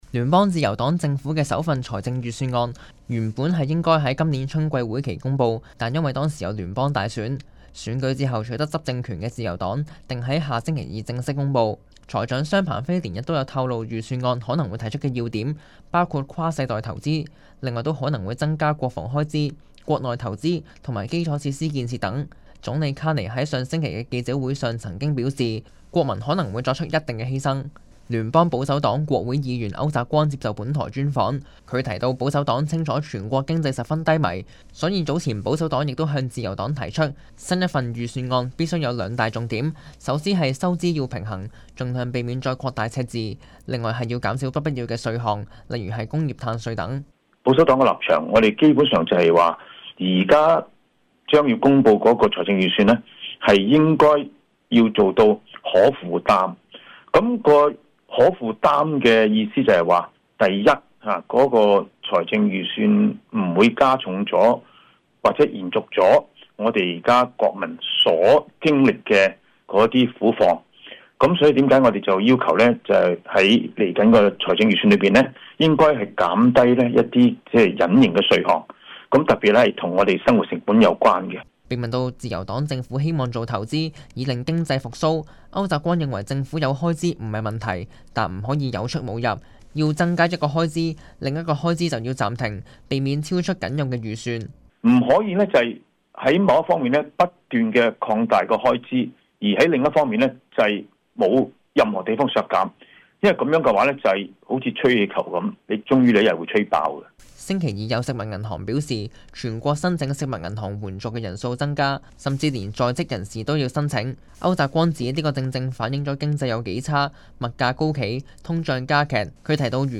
聯邦保守黨國會議員區澤光接受本台專訪，他提到保守黨清楚全國經濟十分低迷，所以早前保守黨亦向自由黨提出，新一份預算案必須要有兩大重點，首先是收支要平衡，盡量避免再擴大赤字；另外是要減少不必要的稅項，例如是工業碳稅等。